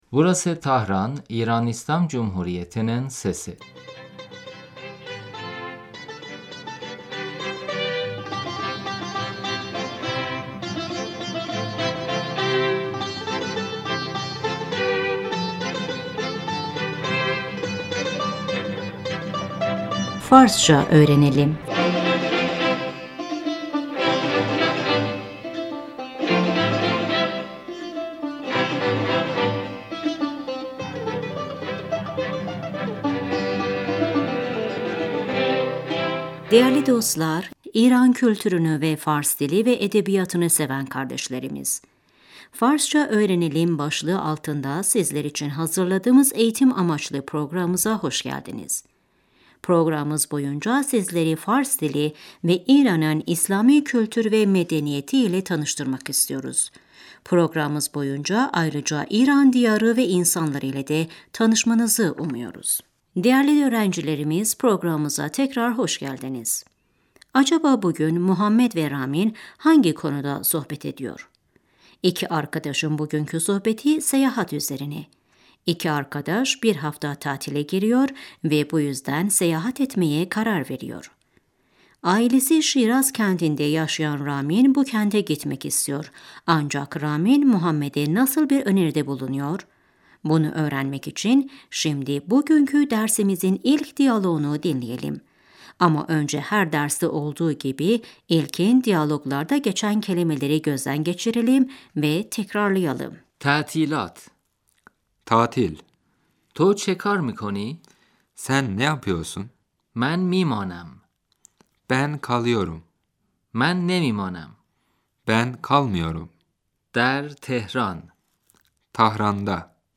صدایترافیکخیابان - قدمزدن Trafik sesi, ayak sesi محمد - رامینتودرتعطیلاتچهکارمیکنی؟